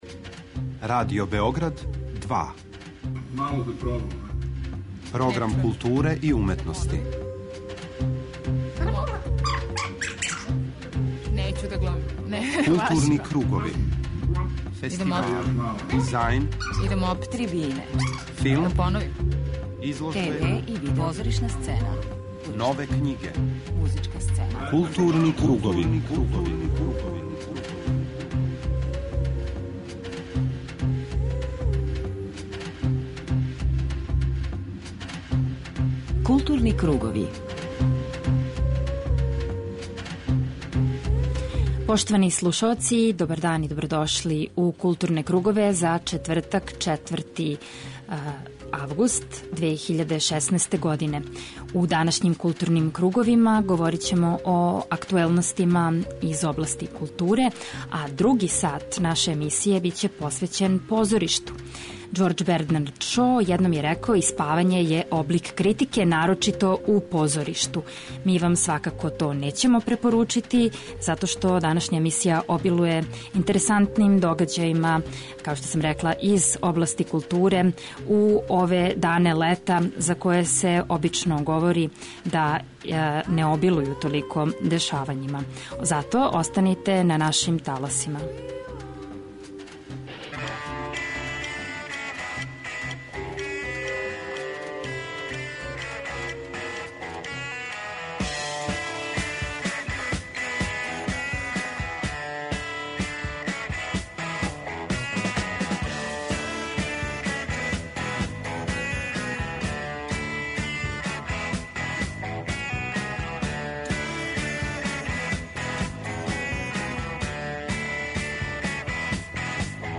преузми : 41.08 MB Културни кругови Autor: Група аутора Централна културно-уметничка емисија Радио Београда 2.